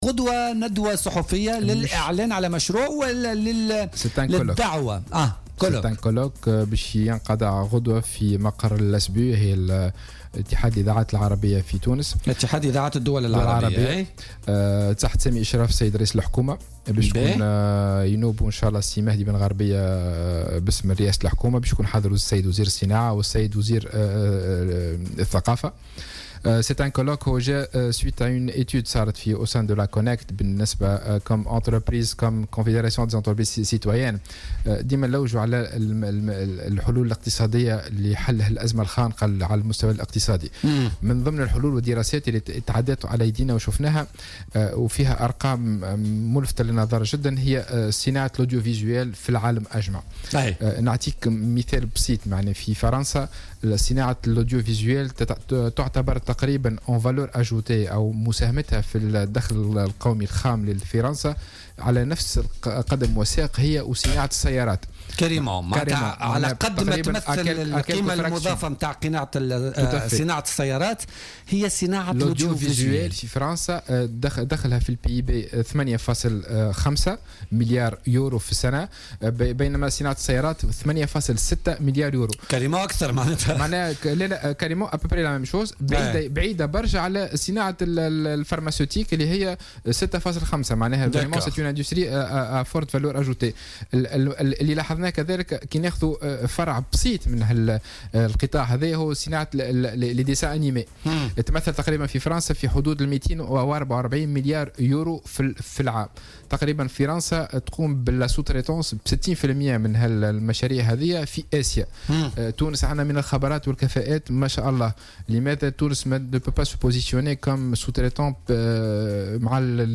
خلال استضافته في برنامج "بوليتيكا"